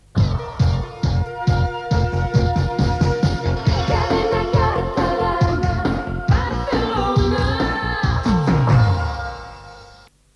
Indicatius de la cadena a Barcelona